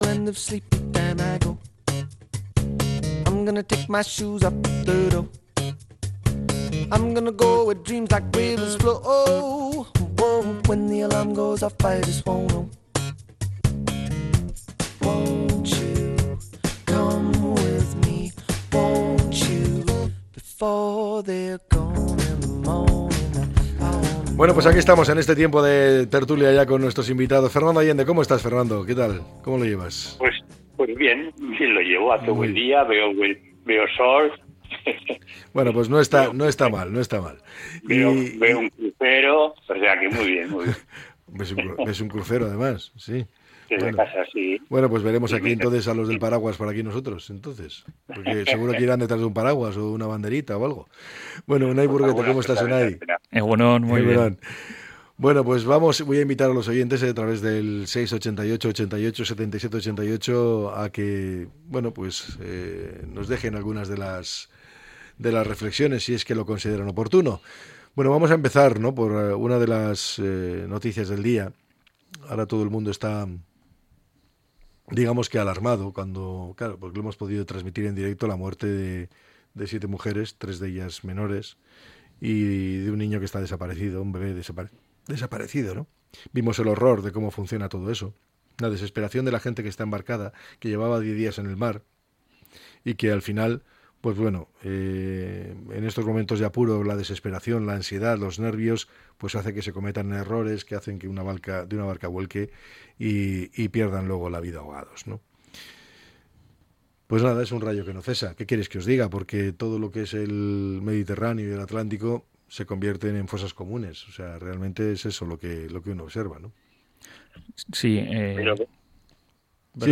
La tertulia 29-05-25.